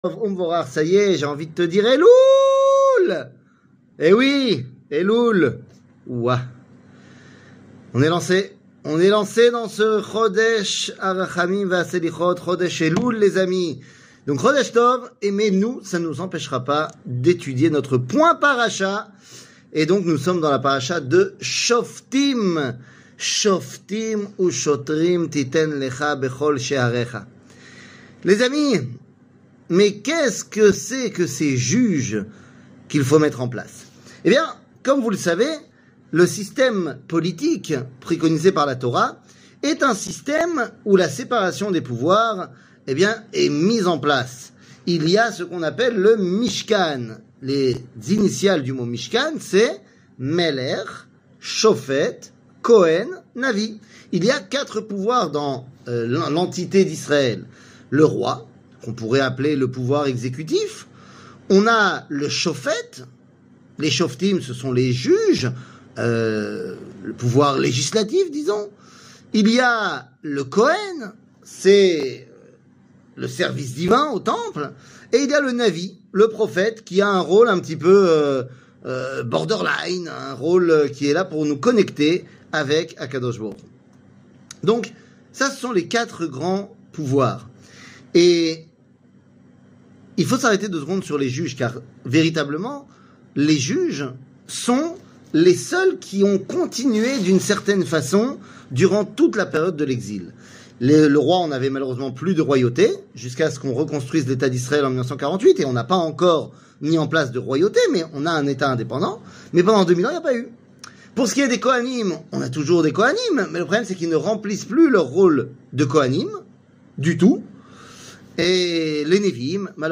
Le point Paracha, Choftim, Les bons juges 00:06:47 Le point Paracha, Choftim, Les bons juges שיעור מ 17 אוגוסט 2023 06MIN הורדה בקובץ אודיו MP3 (6.2 Mo) הורדה בקובץ וידאו MP4 (11.77 Mo) TAGS : שיעורים קצרים